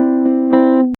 guitar classic teach to learn a basic electronic a resistor content
guitar-classic-teach-to-l-4etnr256.wav